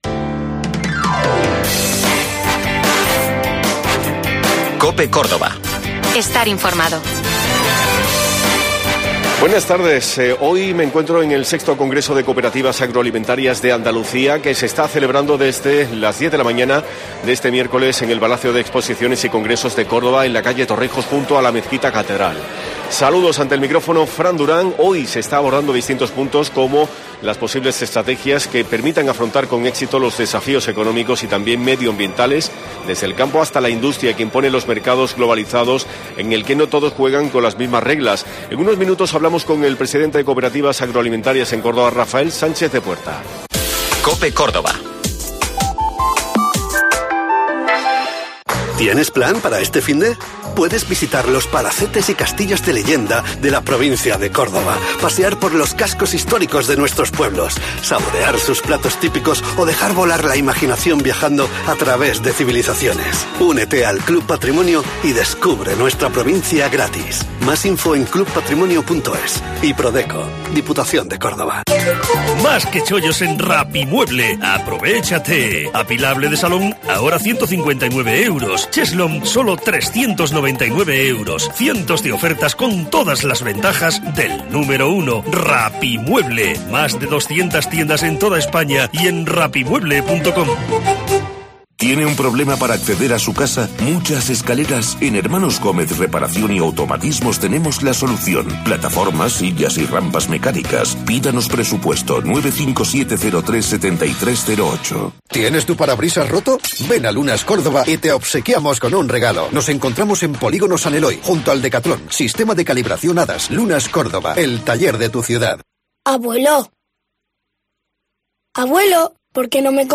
Hoy hemos estado en el VI Congreso de Cooperativas-Agroalimentarias de Andalucía que se está celebrando en el Palacio de Exposiciones y Congresos de Córdoba, en la calle Torrijos, junto a la Mezquita Catedral. En él se está abordando distintos puntos, como las posibles estrategias que permitan afrontar con éxito los desafíos económicos y también medioambientales, desde el campo hasta la industria, que impone los mercados globalizados en el que no todos juegan con las mismas reglas.